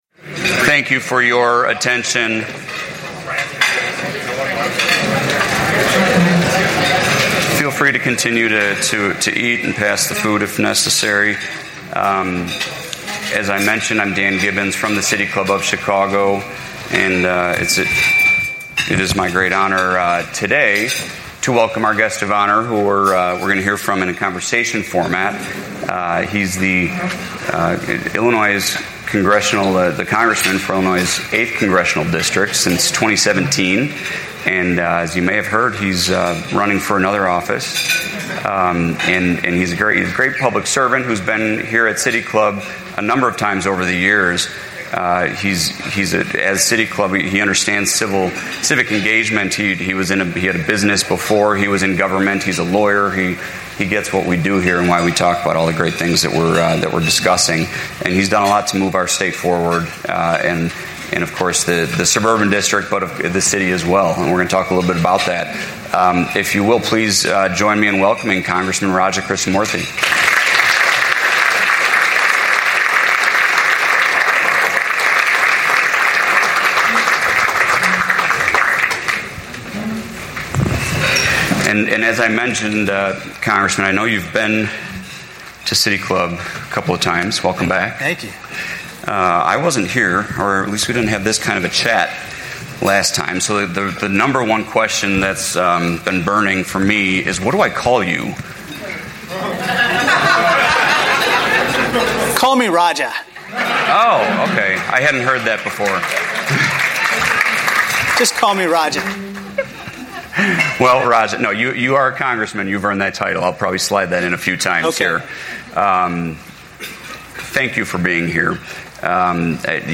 Congressman Raja Krishnamoorthi, Illinois’ 8th District, in Conversation